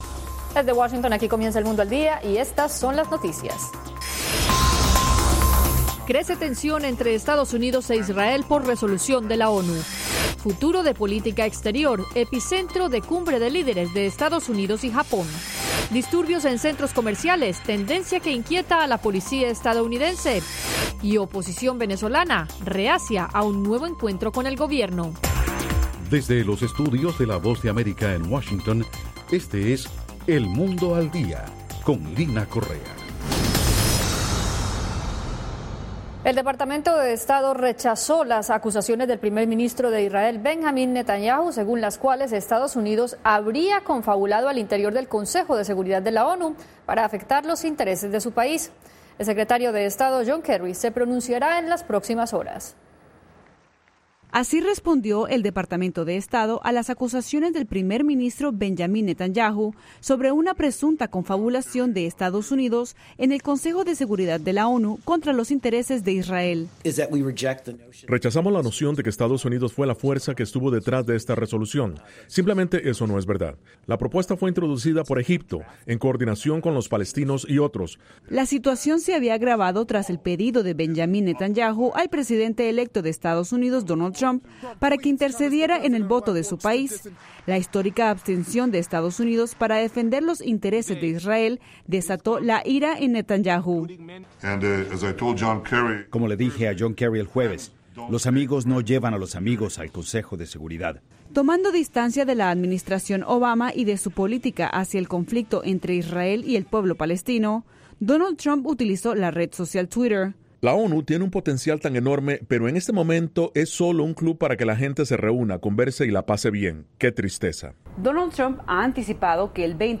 Las noticias del acontecer de Estados Unidos y el mundo